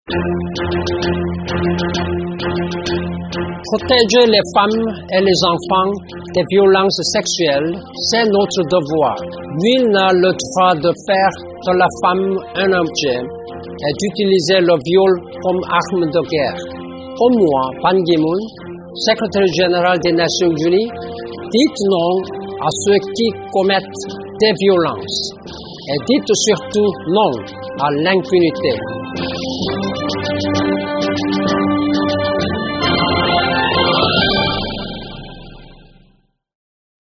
Ecoutez ici les messages de Ban Ki-moon, secréteur général de l’ONU, à l’occasion de la campagne de lutte contre les violences faites aux femmes et aux enfants: